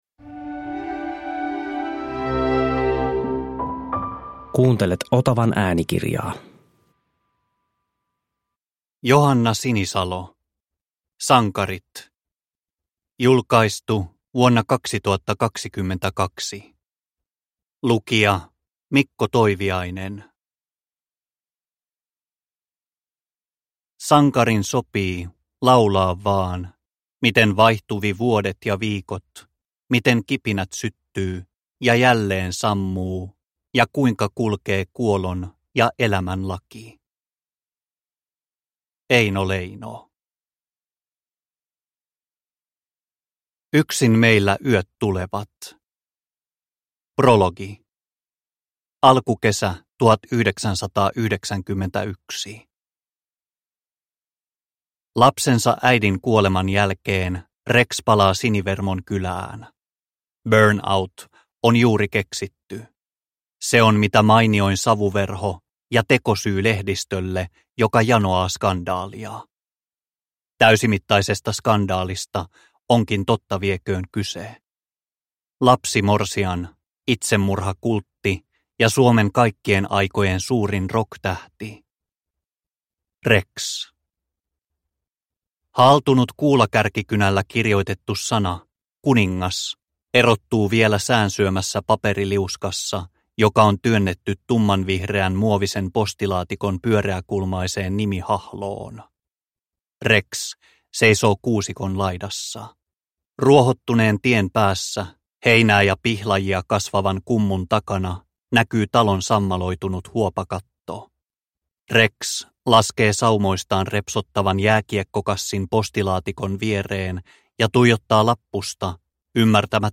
Sankarit – Ljudbok – Laddas ner